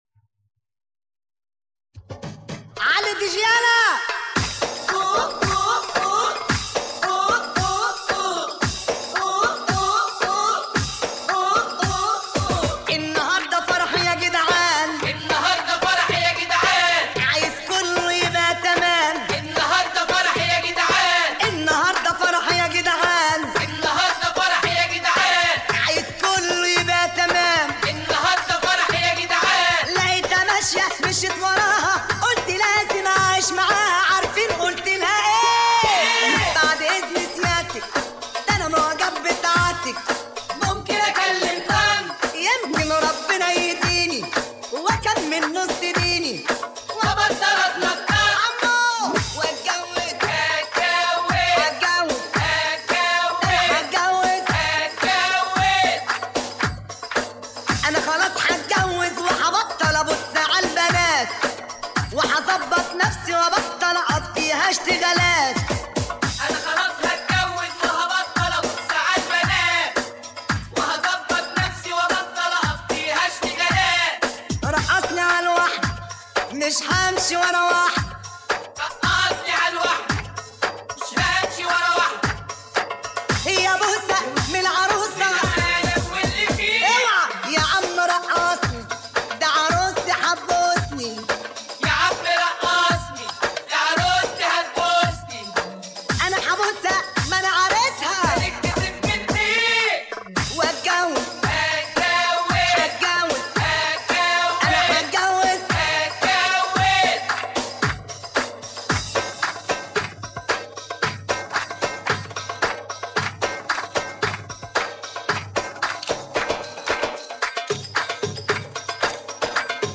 أغانيك المفضلة بدون المعازف الموسيقية